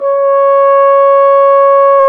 Index of /90_sSampleCDs/Roland L-CDX-03 Disk 2/BRS_French Horn/BRS_Mute-Stopped
BRS F HRN 09.wav